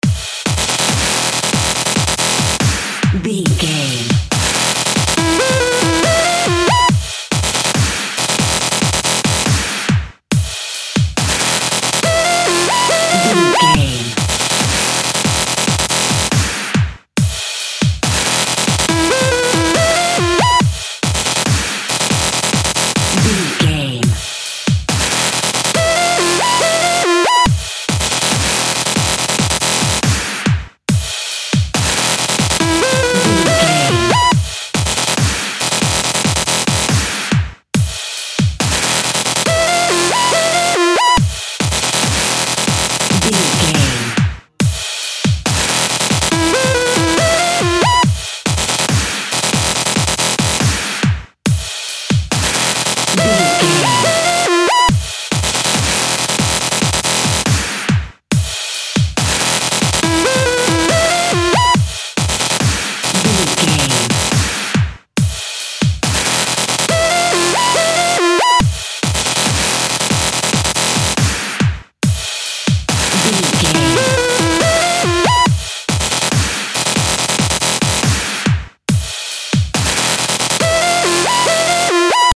Epic / Action
Fast paced
Aeolian/Minor
aggressive
dark
driving
intense
drum machine
synthesiser
electronic
synth lead
synth bass
synth drums